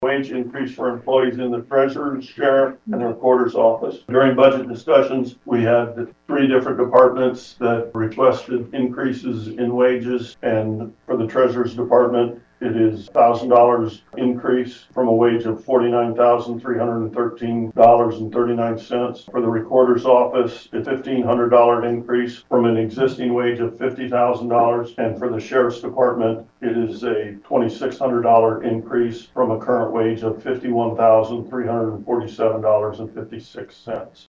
This is Wright County Supervisor Dean Kluss.